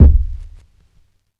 Kicks
Medicated Kick 28.wav